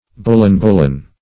Search Result for " bullen-bullen" : The Collaborative International Dictionary of English v.0.48: Bullen-bullen \Bul"len-bul"len\, n. [Native Australian name, from its cry.]